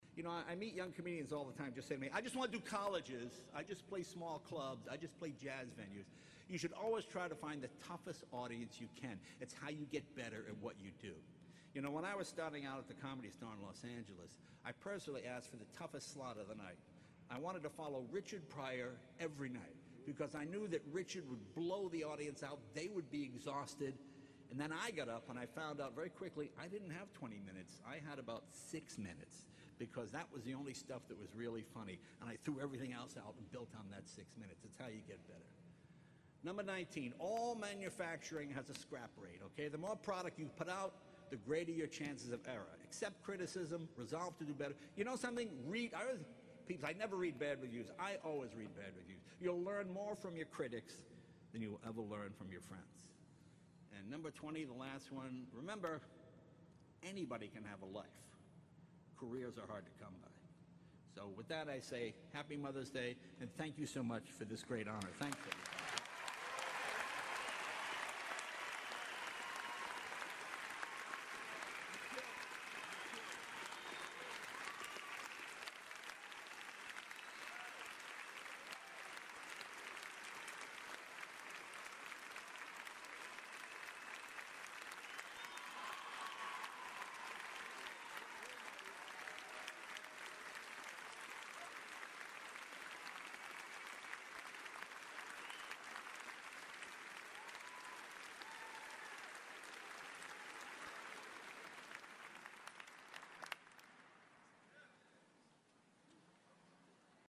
公众人物毕业演讲 第214期:杰雷诺2014爱默生学院(12) 听力文件下载—在线英语听力室